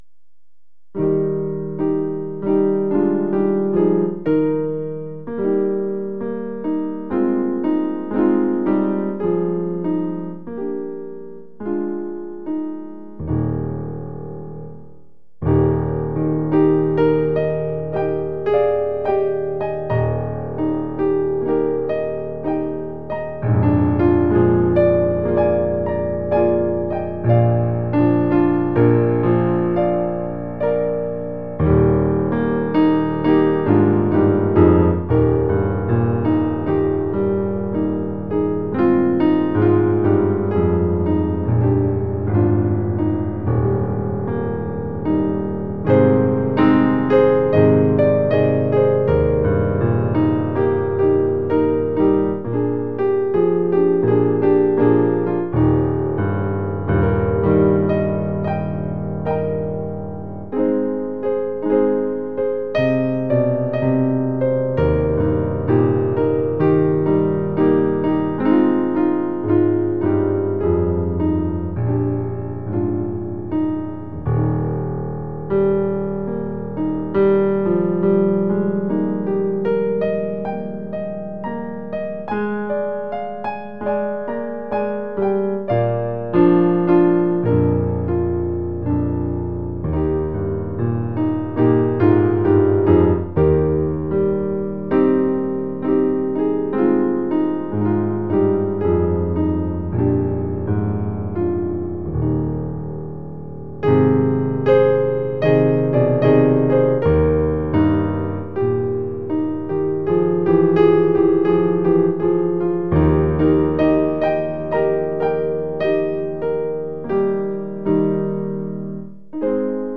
Description:Traditional Hymn
Piano version - MP3 file @ 192Kbps